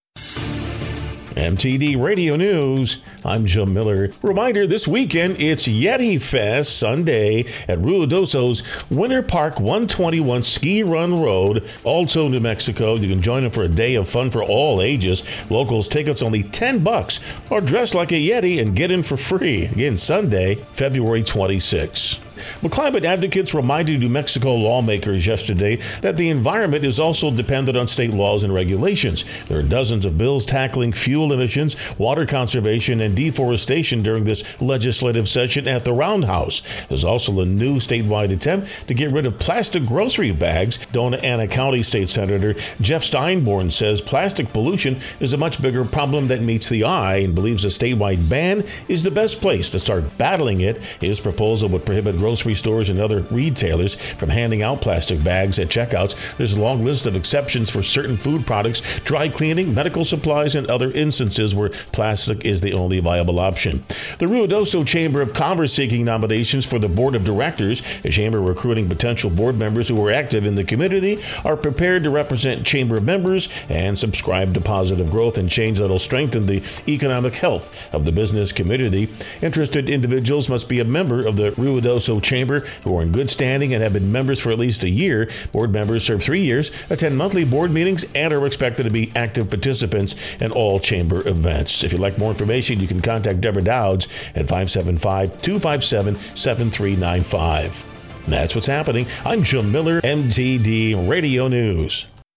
KIDX NEWS FEB. 24, 2023